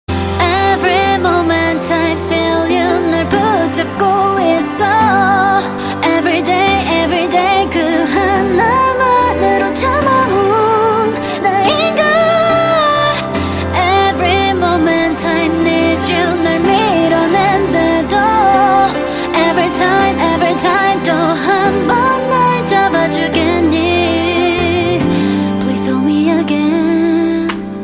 M4R铃声, MP3铃声, 日韩歌曲 30 首发日期：2018-05-15 07:31 星期二